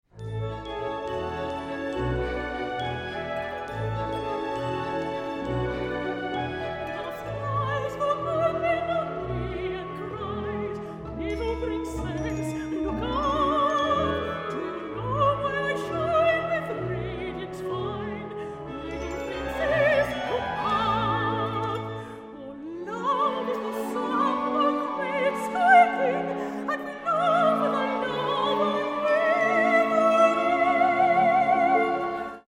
Arias from British Operas